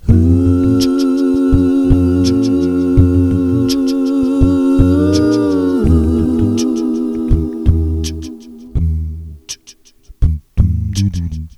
Best Of Free Male Vocal Samples